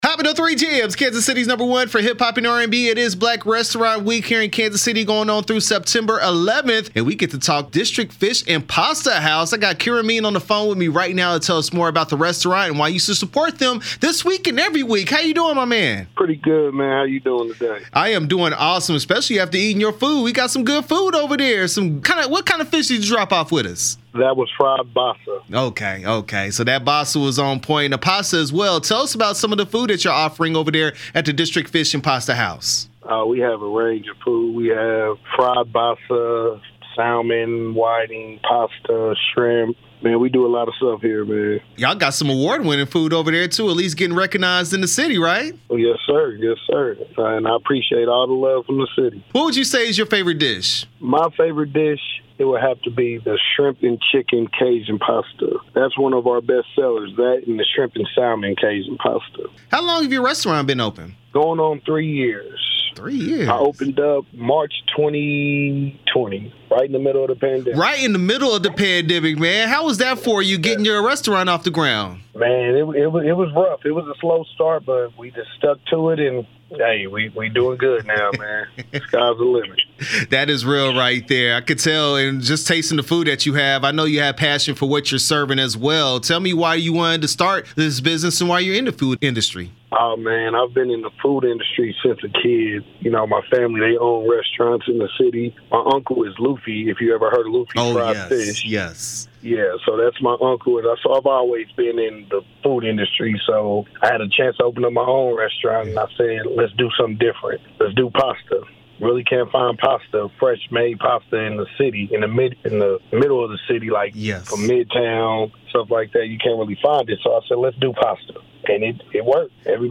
Black Restaurant Week District Fish N Pasta House interview 9/7/22